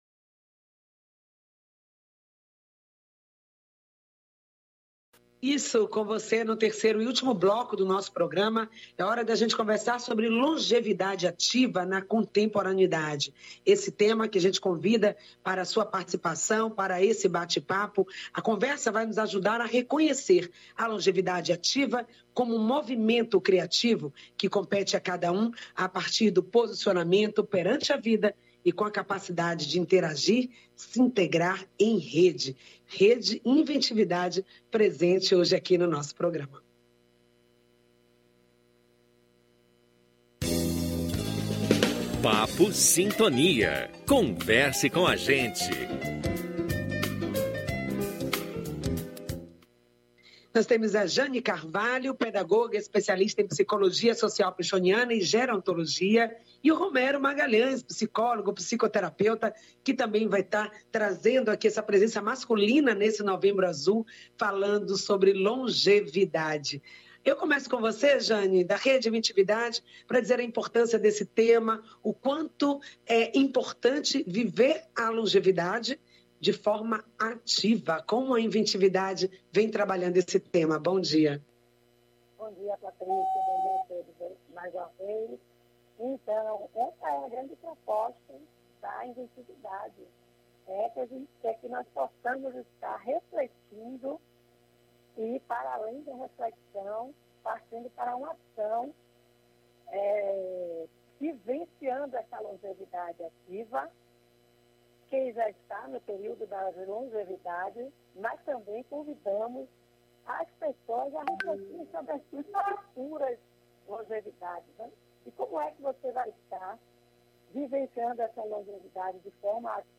A conversa nos ajuda reconhecer a longevidade ativa como um movimento criativo que compete a cada um, a partir do posicionamento perante a vida e com a capacidade de interagir e se integrar em rede. A entrevista é um convite a repensar as lógicas da existência do indivíduo na sua trajetória no seu Espaço/Tempo.